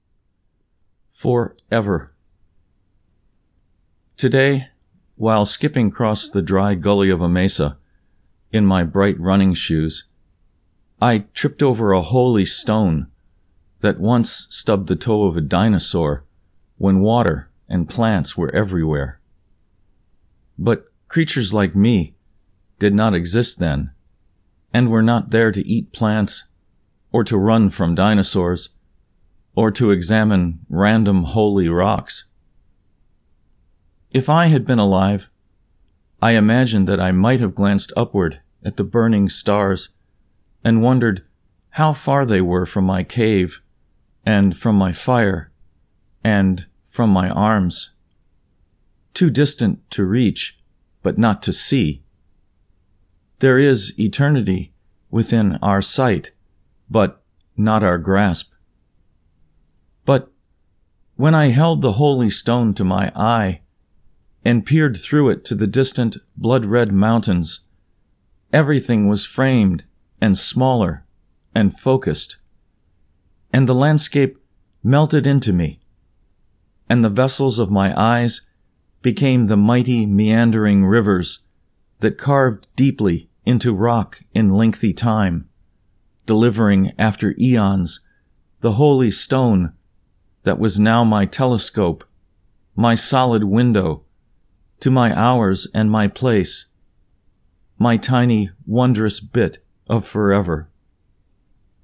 For Ever (Recitation)